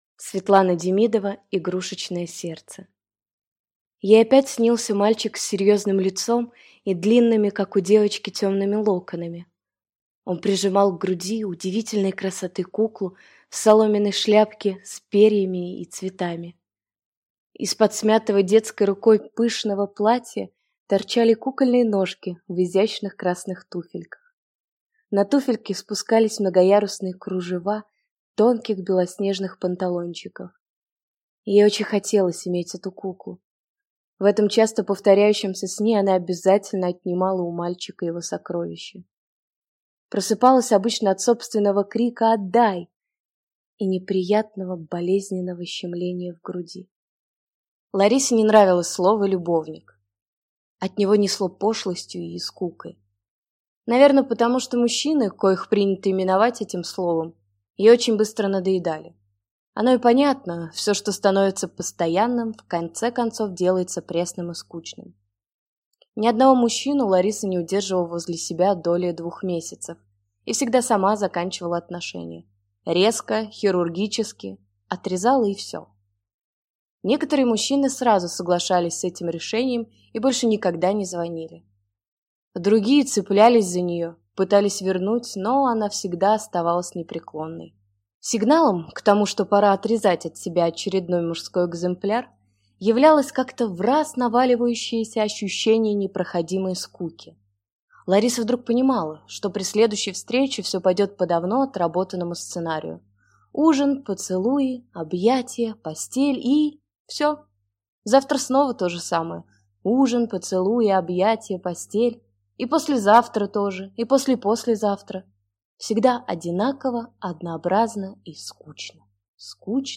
Аудиокнига Игрушечное сердце | Библиотека аудиокниг
Прослушать и бесплатно скачать фрагмент аудиокниги